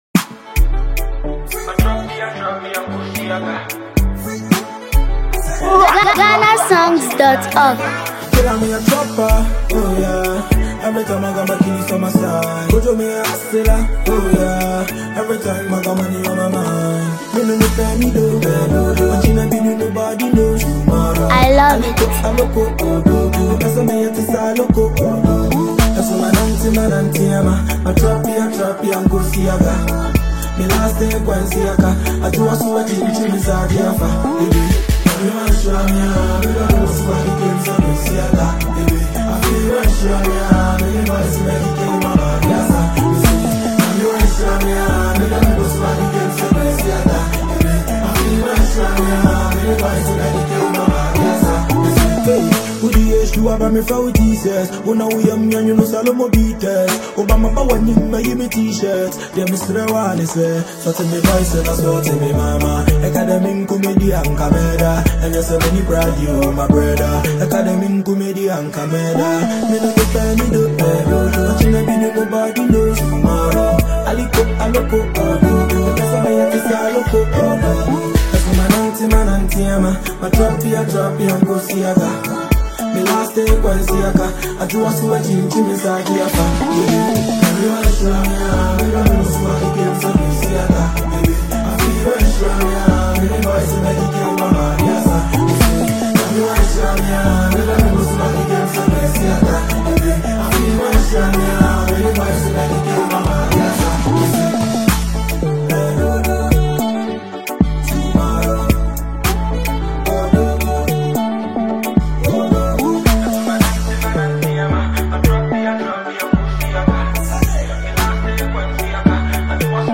Ghanaian rapper
delivers confident bars and relatable messages